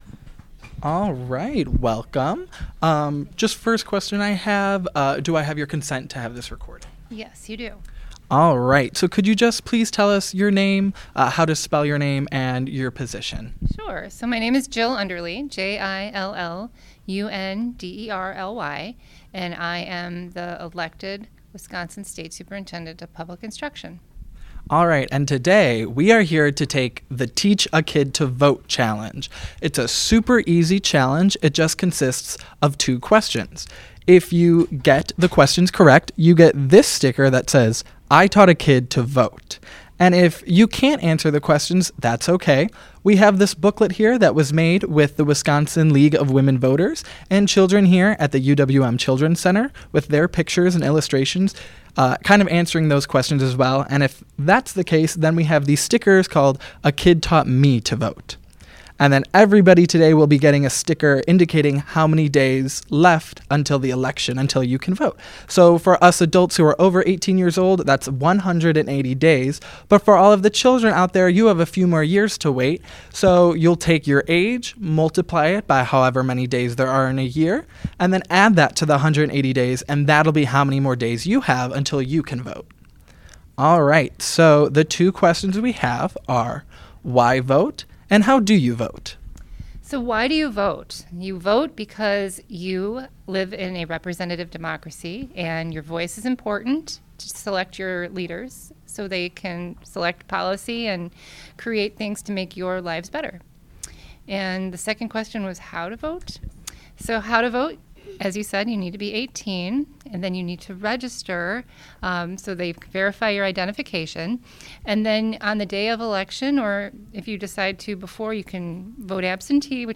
Superintendent Underly Interview
Location UWM Children's Learning Center